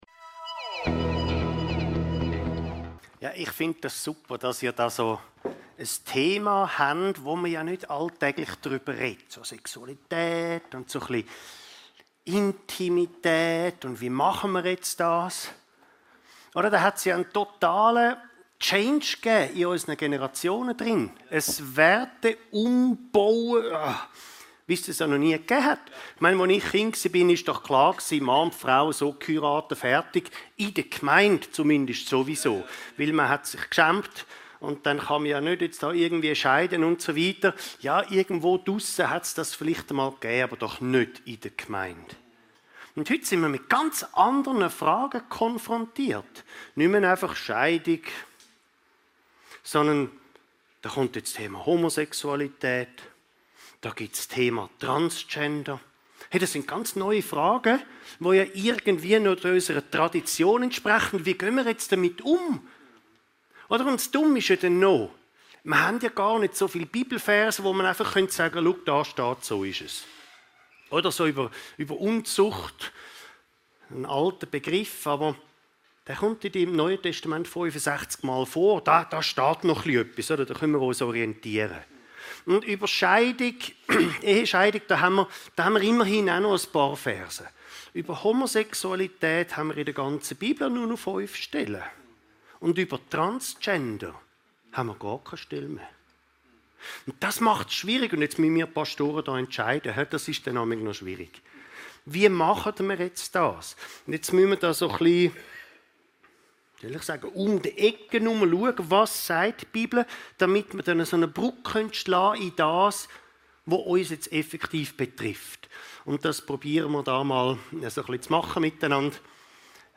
Umgang mit Intimität (Sexualität) ~ Your Weekly Bible Study (Predigten) Podcast